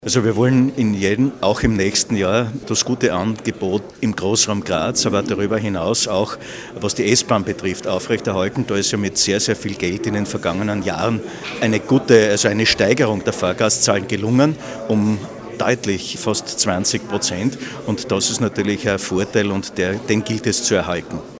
Landesrat Gerhard Kurzmann: